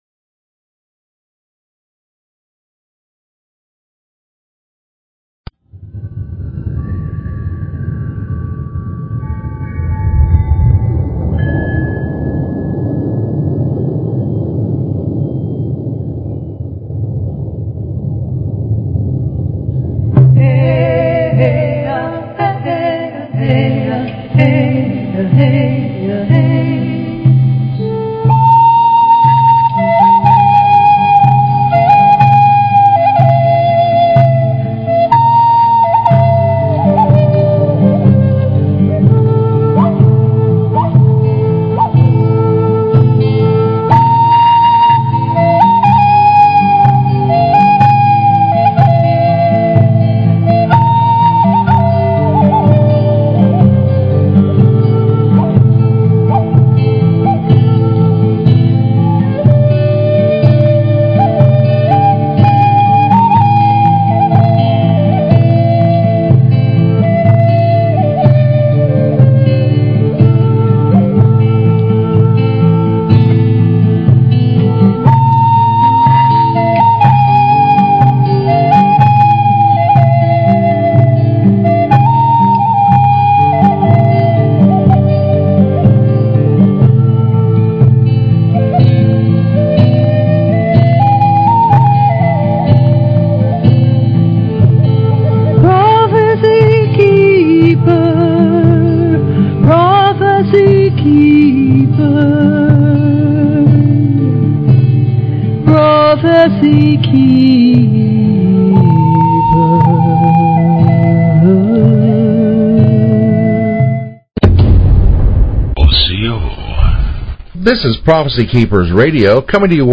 Talk Show Episode, Audio Podcast, Prophecykeepers_Radio and Courtesy of BBS Radio on , show guests , about , categorized as